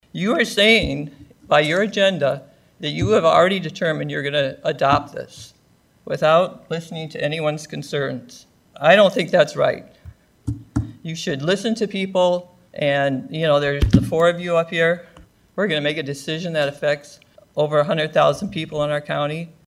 ONE WHO SPOKE SAID IT APPEARED THE SUPERVISORS HAD ALREADY MADE UP THEIR MINDS NO MATTER WHAT INPUT THEY RECEIVED: